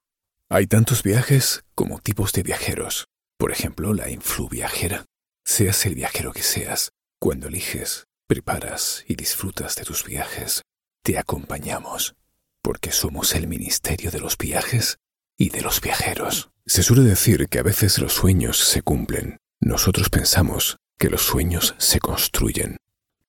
Unternehmensvideos
Ich kann mit englischem und andalusischem Akzent sprechen.
BaritonTiefNiedrig